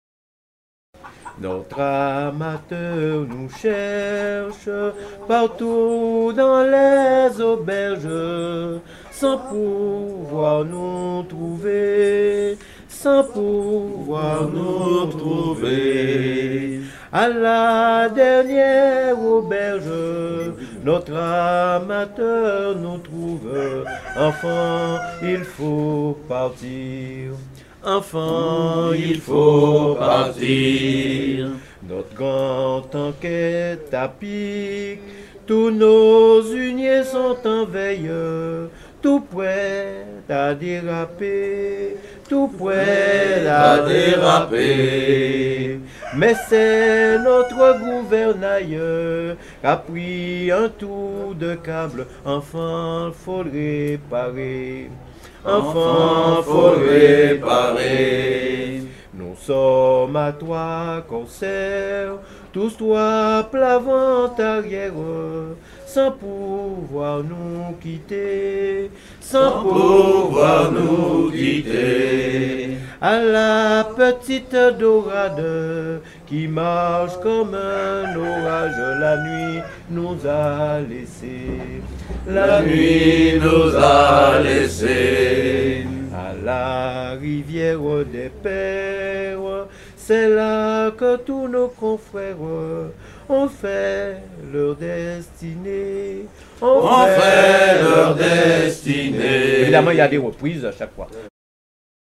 enregistré sur l'île de La Désirade en 2002
Genre laisse
Pièce musicale éditée